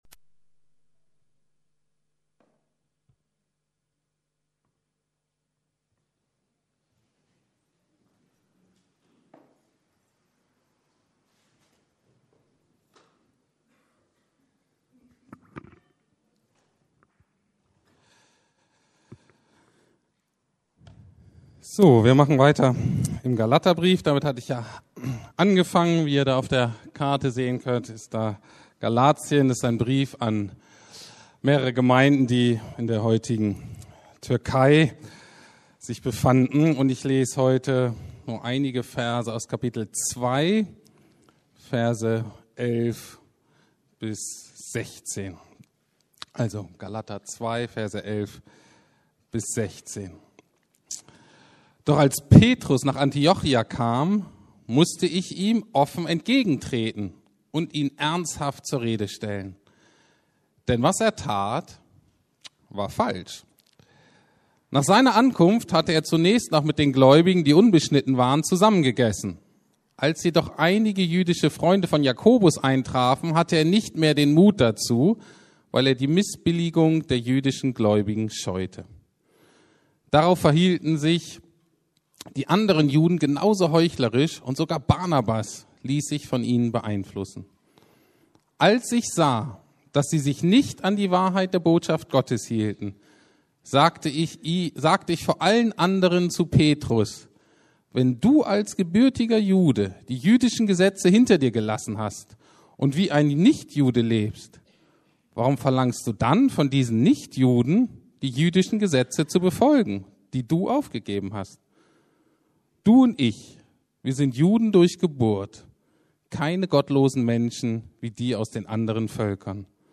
Unser Leben am Evangelium ausrichten ~ Predigten der LUKAS GEMEINDE Podcast